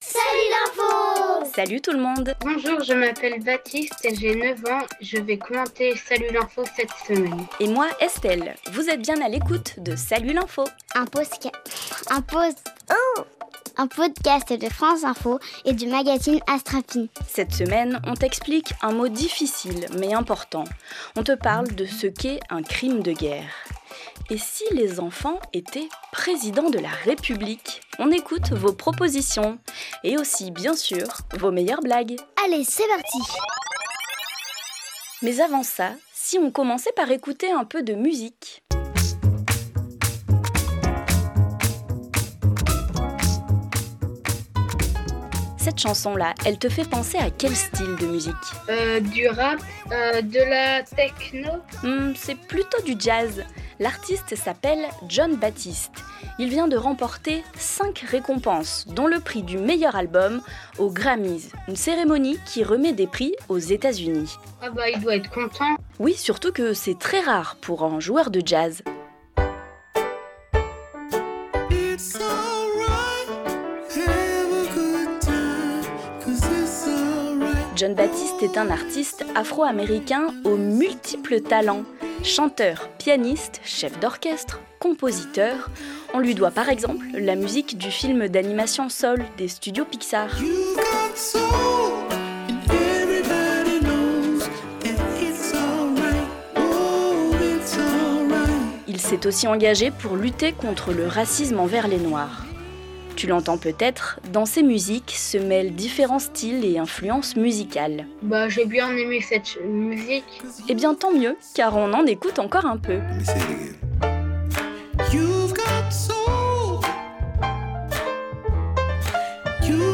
Cette semaine : des enfants racontent ce qu’ils feraient s’ils étaient élus président ; l’expression “crime de guerre” ; la cigarette…